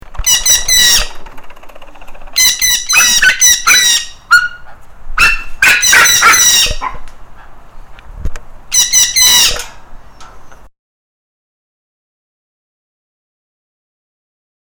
The din of the two of those jealous pet siblings is enough to make anyone insane.
THE DIN OF INSANITY-A RECORDING OF BIRD SCREECHES AND YAPPING
He is a Sun Conure.
the-din-of-insanity-a-recording-of-bird-screeches-and-yapping.mp3